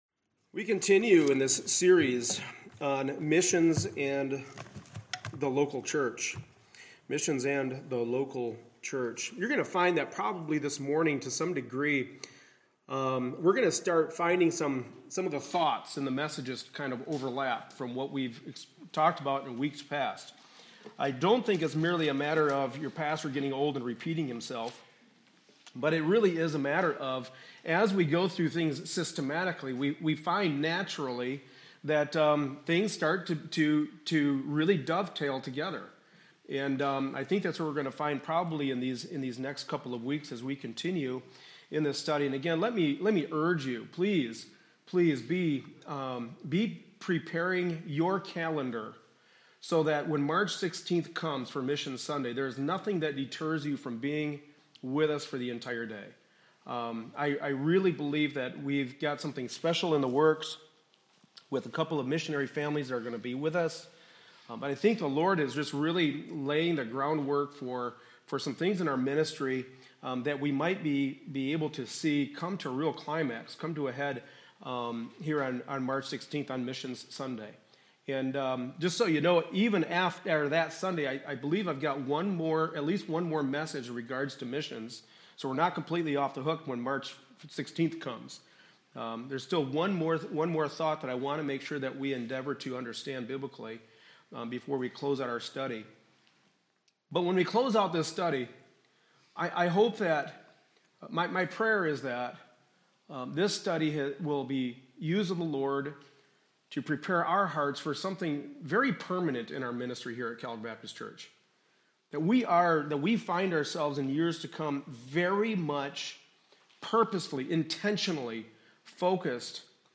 Passage: 2 Corinthians 8:1-15 Service Type: Sunday Morning Service